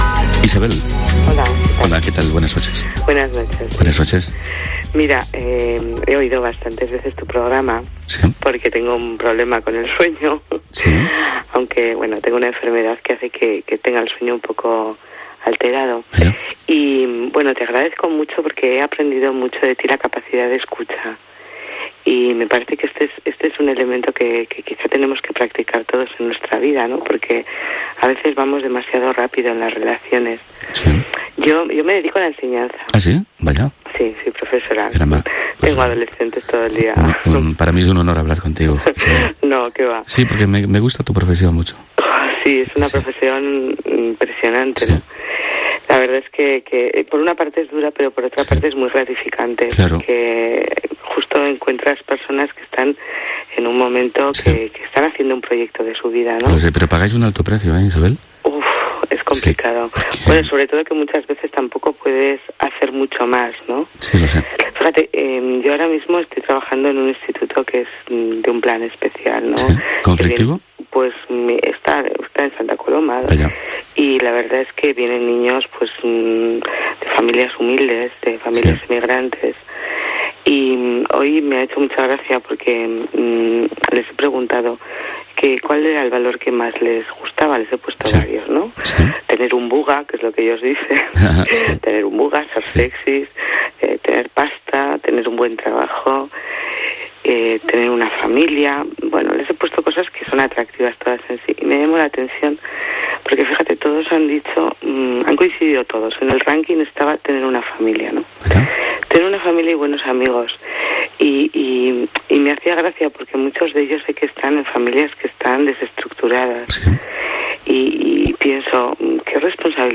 Eso explica la buena audiencia de “Momentos”, un programa donde los oyentes pueden ser escuchados y también comprendidos; explicar sus problemas e inquietudes.